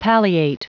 pronounced 'pale e ate ed'; to make an offense or crime or disease seem less serious; extenuate; to make less severe or intense; mitigate; to relieve the symptoms of a disease or disorder